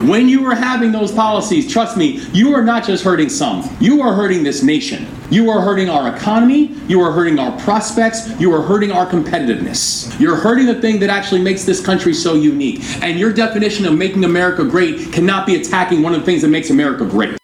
In his acceptance speech, Moore took the opportunity to call out Trump policies that he says are intentionally hurting Black and Brown Americans…